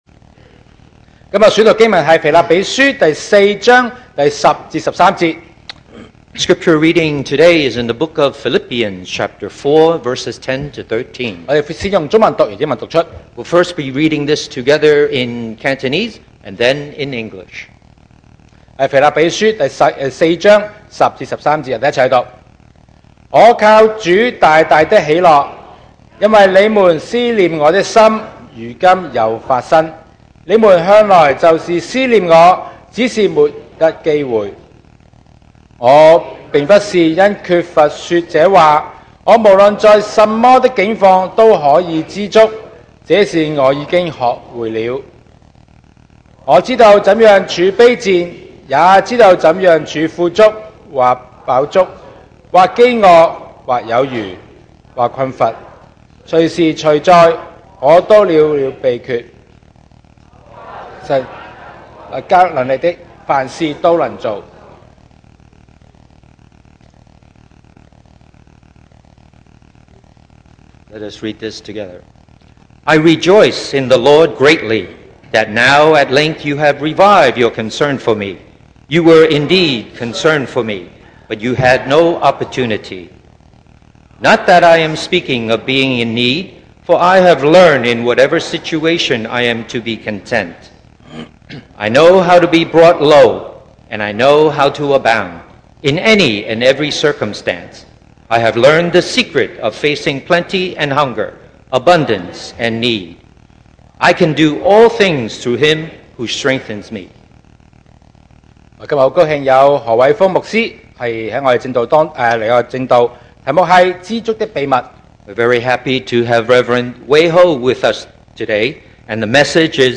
2025 sermon audios
Service Type: Sunday Morning